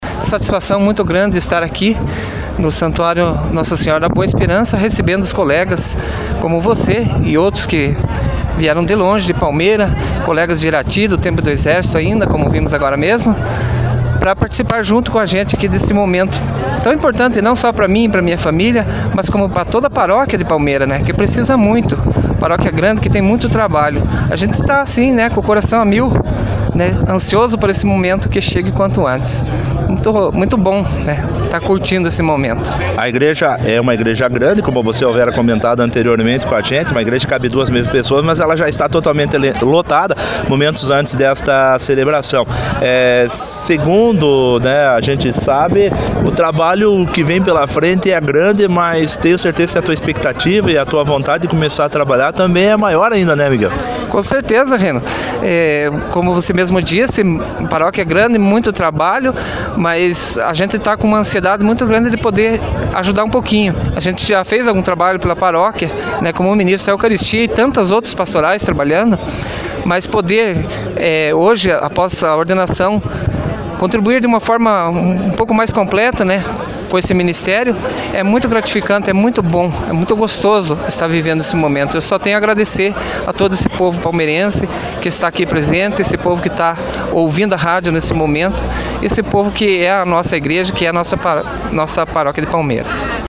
Minutos antes da ordenação